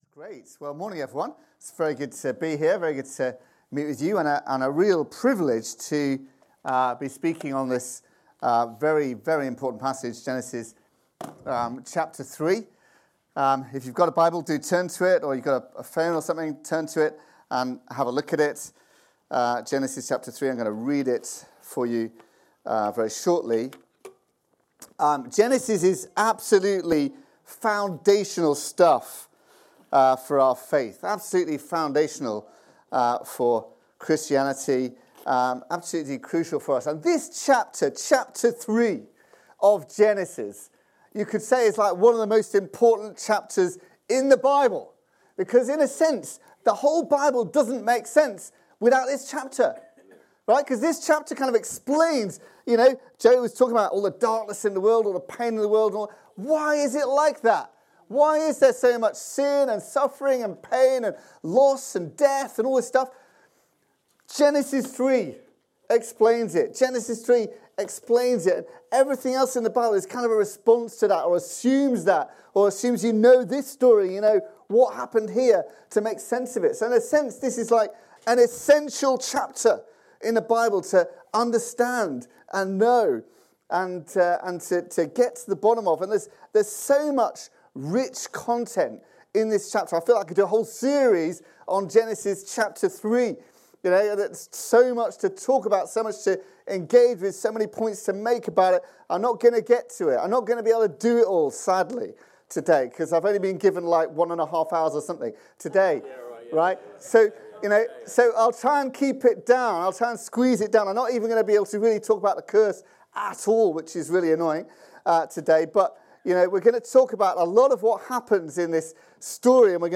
Download When Everything Breaks | Sermons at Trinity Church